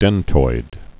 (dĕntoid)